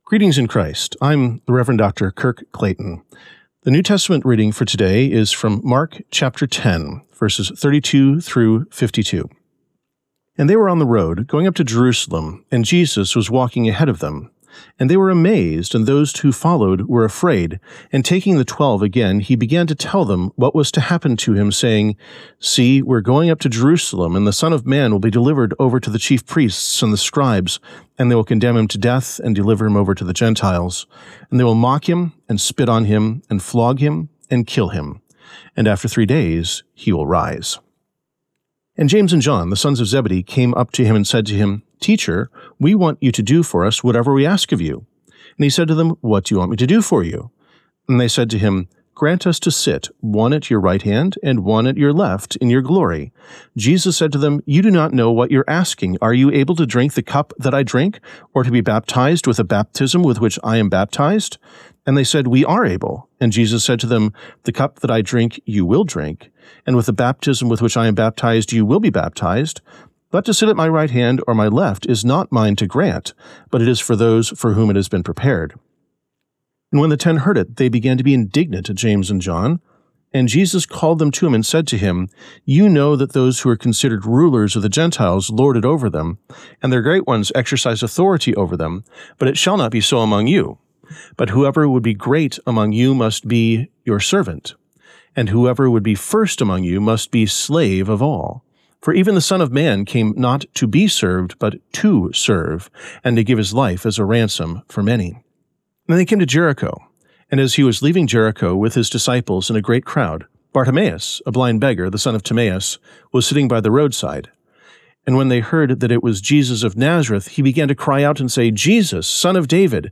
Morning Prayer Sermonette: Mark 10:32-52
Hear a guest pastor give a short sermonette based on the day’s Daily Lectionary New Testament text during Morning and Evening Prayer.